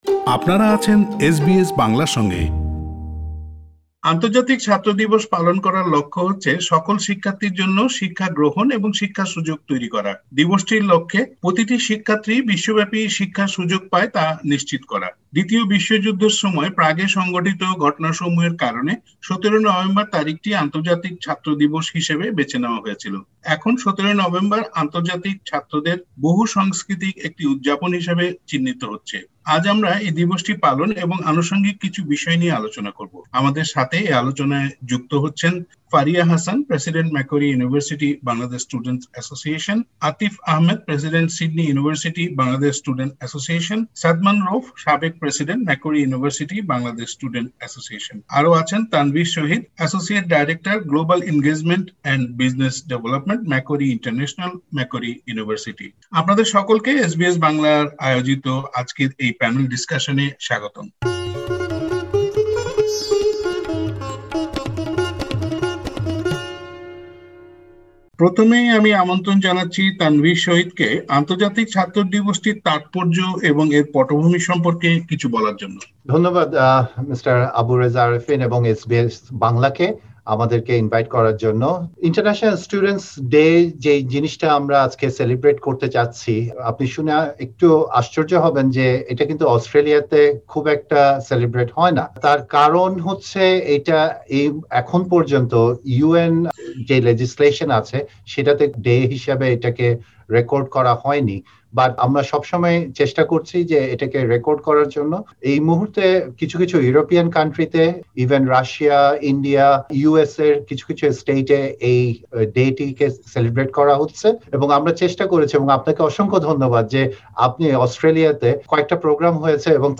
১৭ নভেম্বর ছিল আন্তর্জাতিক শিক্ষার্থী দিবস। এসবিএস বাংলার সঙ্গে কথা বলেছেন কয়েকজন শিক্ষার্থী ও একজন শিক্ষক।